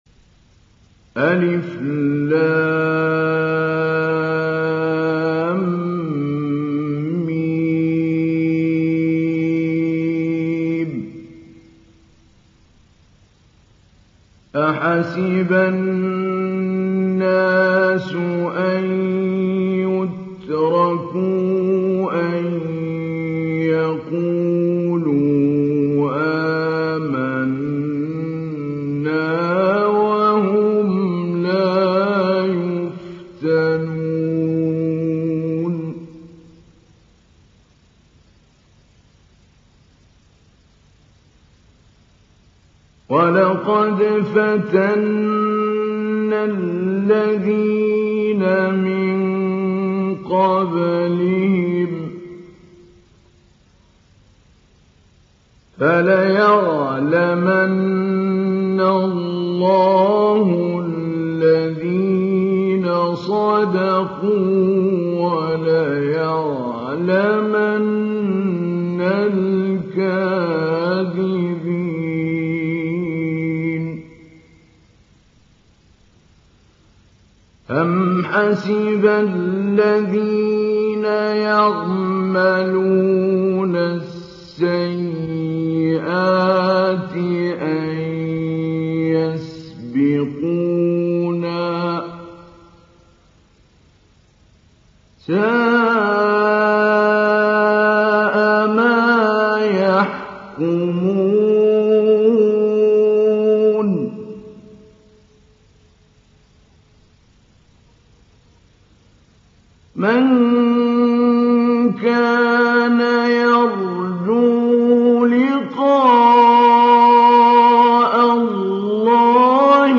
دانلود سوره العنكبوت mp3 محمود علي البنا مجود روایت حفص از عاصم, قرآن را دانلود کنید و گوش کن mp3 ، لینک مستقیم کامل
دانلود سوره العنكبوت محمود علي البنا مجود